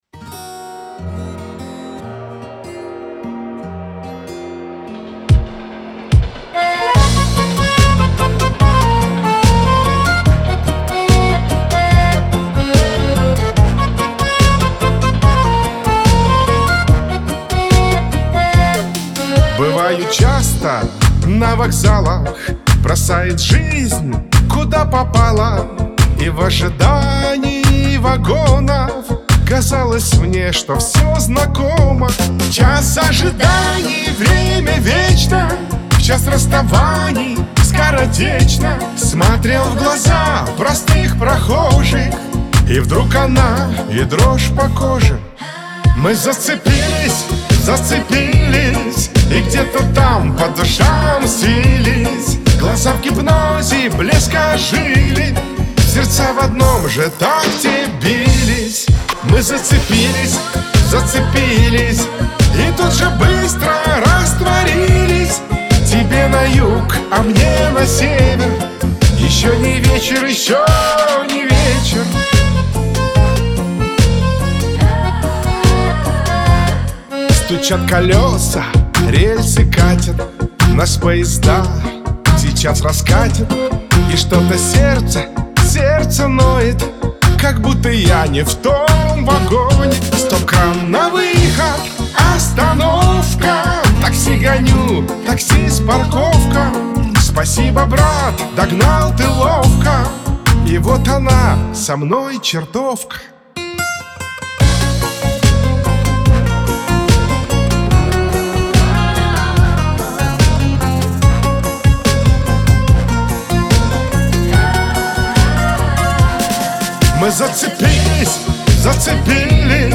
эстрада , pop